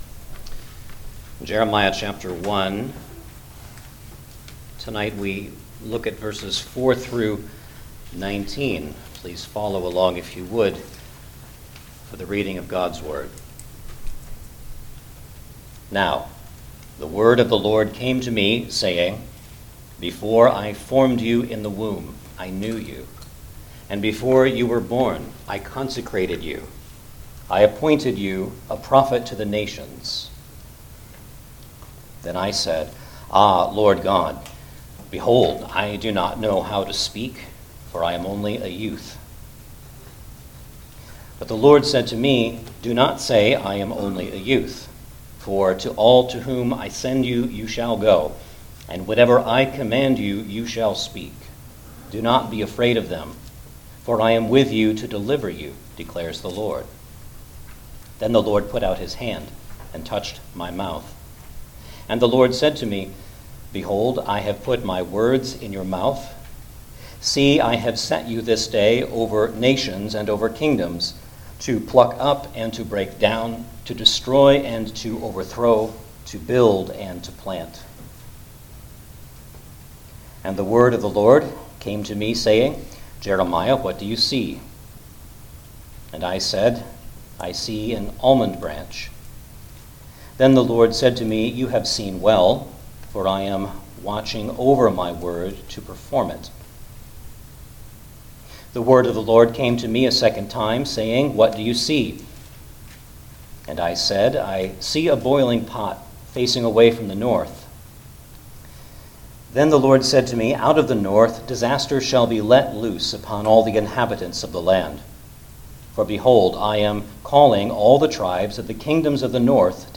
Jeremiah Passage: Jeremiah 1:4-19 Service Type: Sunday Evening Service Download the order of worship here .